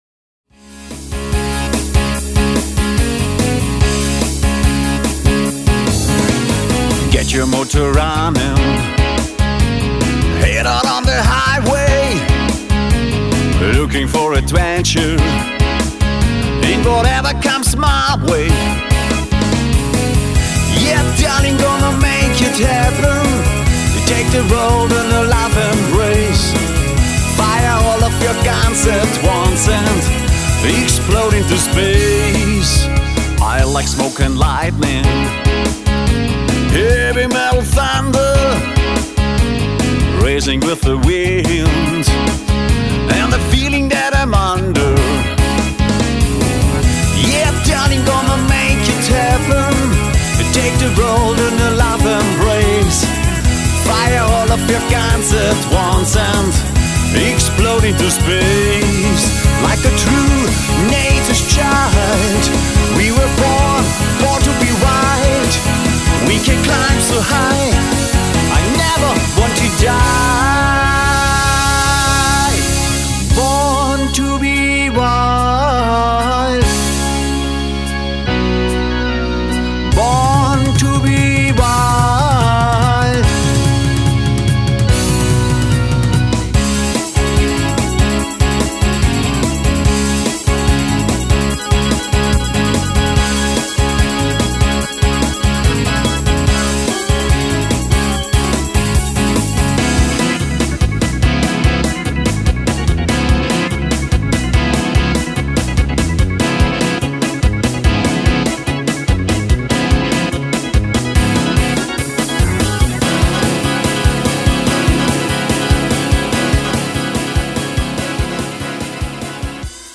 Duo
• Keine Playbacks